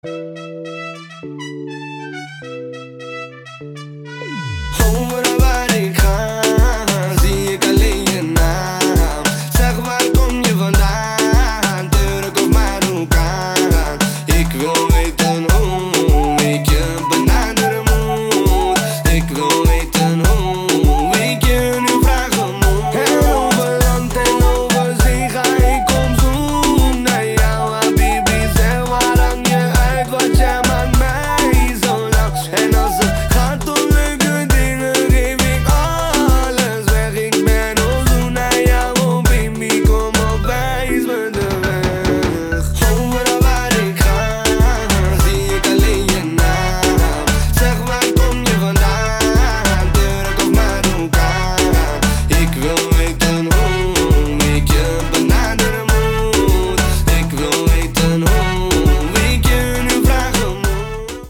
• Качество: 256, Stereo
ритмичные
мужской вокал
восточные мотивы
dance
спокойные
Флейта
труба